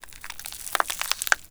ALIEN_Insect_06_mono.wav